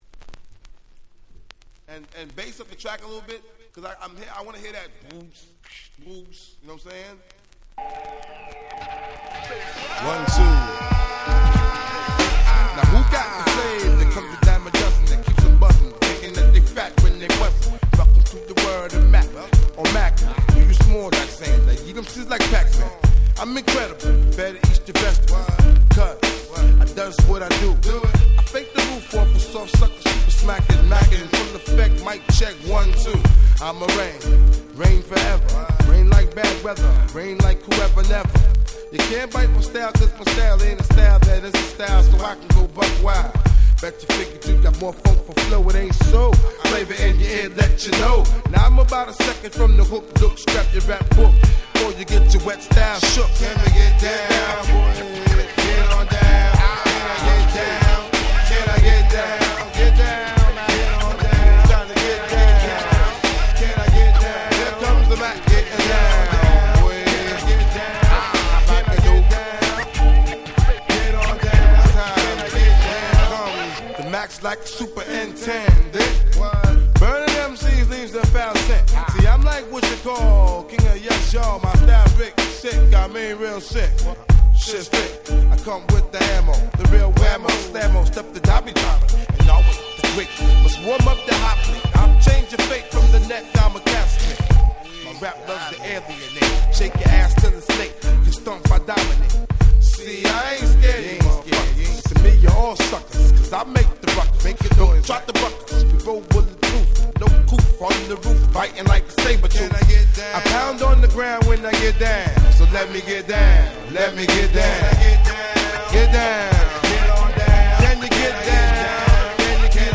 95' Smash Hit Hip Hop !!
90's Boom Bap ブーンバップ